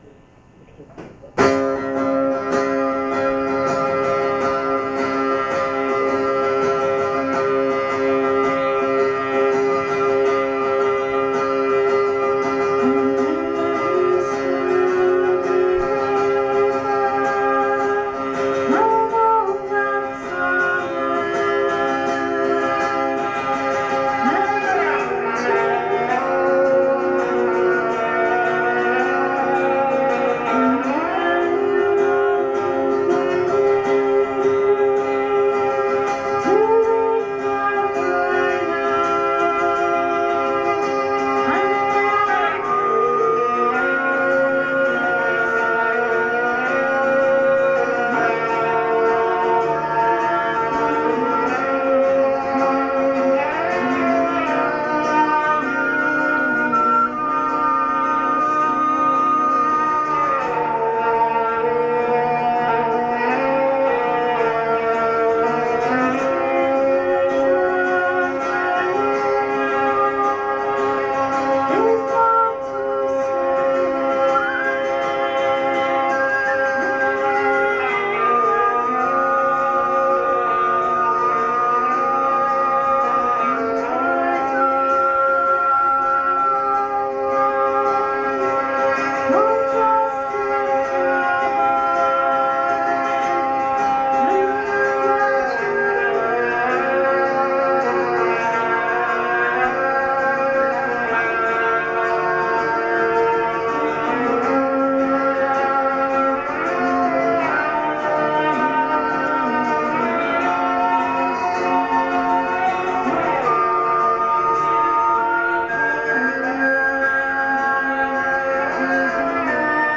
there are some lovely wind additions
Knitting Factory, Los Angeles, CA, USA